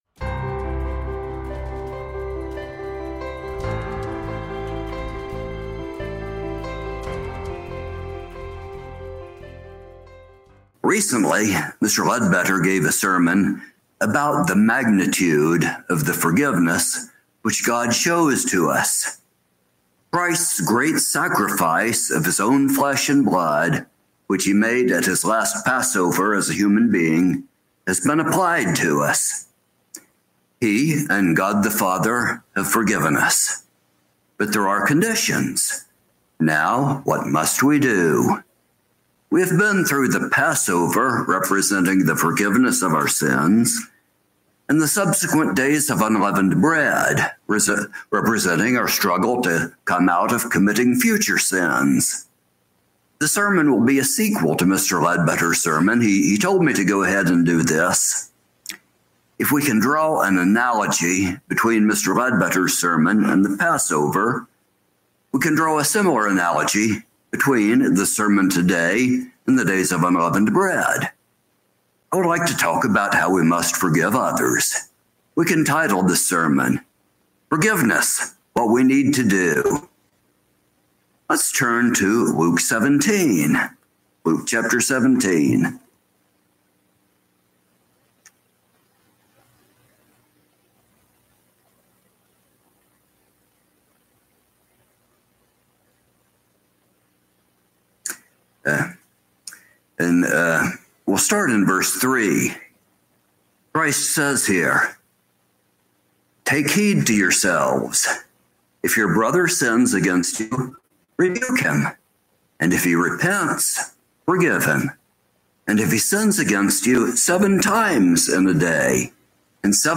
Given in Kingsport on Sabbath, May 11, 2024